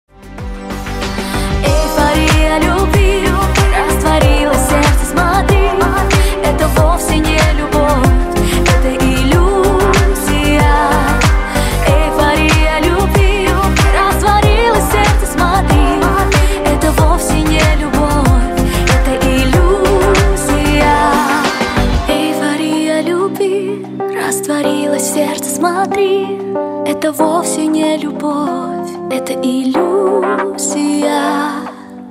танцевальный трэк